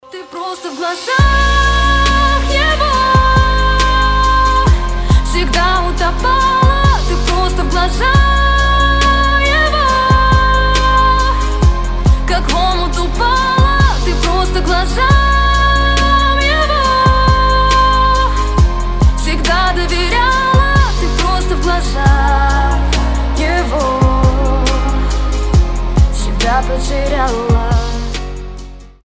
• Качество: 320, Stereo
женский голос
красивый вокал